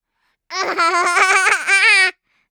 tickle4.ogg